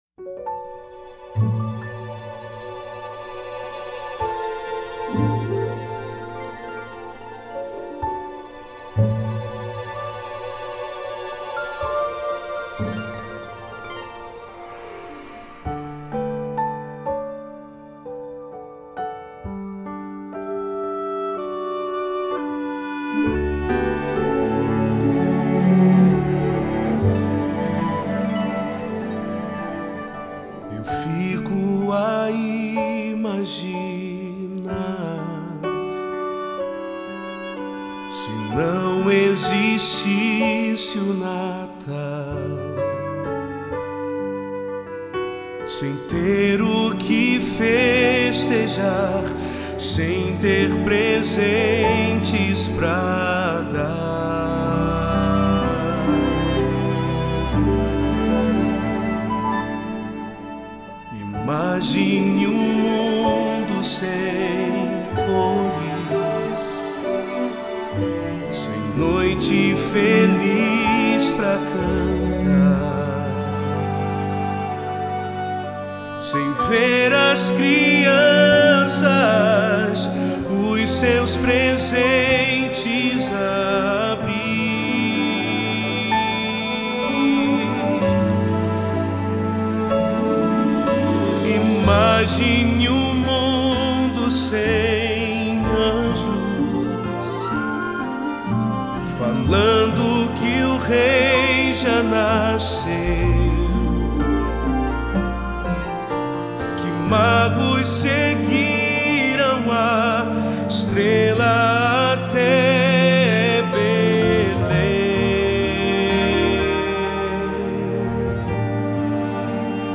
sua próxima cantata de Natal.
começa com um lindo solo